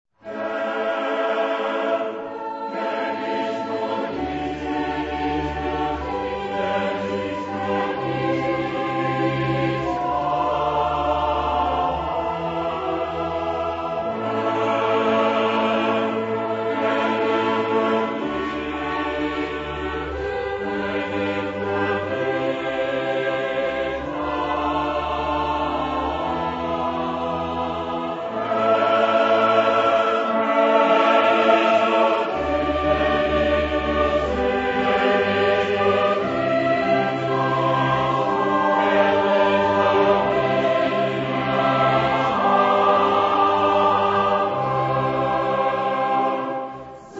Genre-Style-Forme : Sacré ; Psaume ; Baroque
Caractère de la pièce : solennel
Type de choeur : SATB + SATB  (8 voix Double Chœur OU mixtes )
Instruments : Basse continue
Tonalité : la mineur